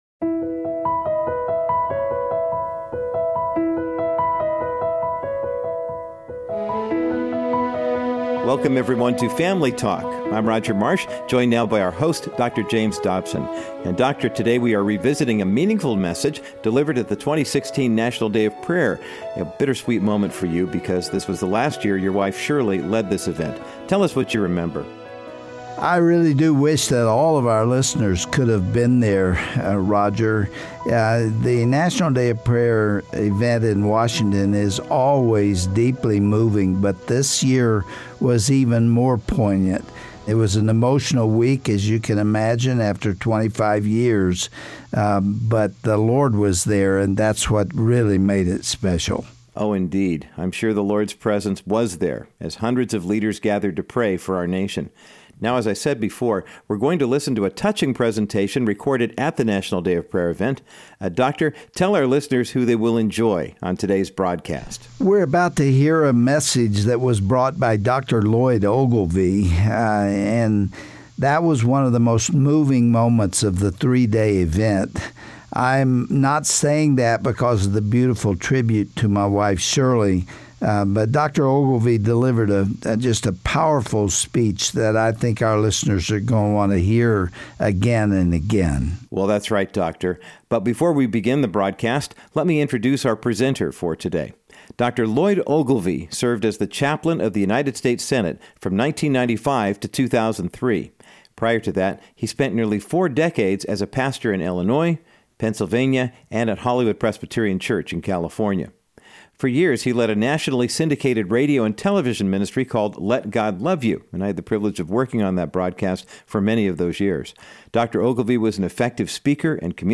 Our founding fathers knew that for America to thrive, its people would need to rely on God. Listen as the late Dr. Lloyd Ogilvie delivers a powerful message on this topic at the 2016 National Day of Prayer.